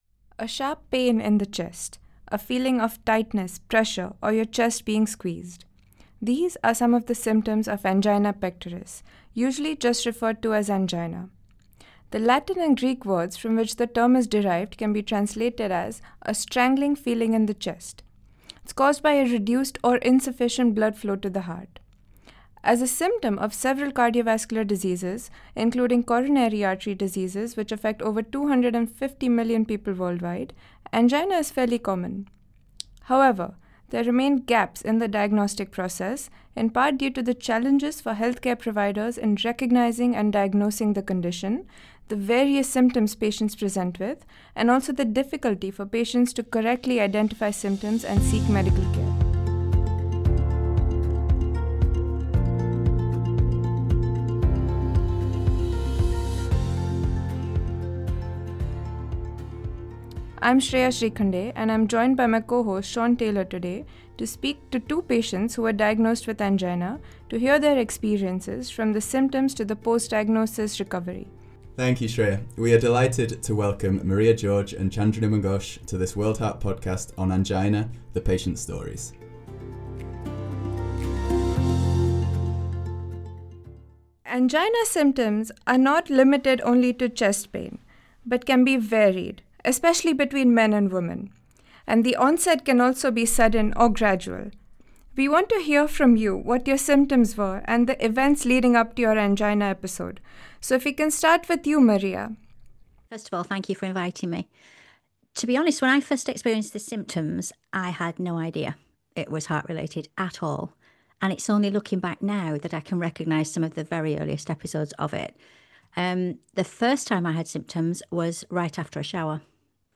World Heart Podcast - Episode 15: Conversations from the Heart: Living with Angina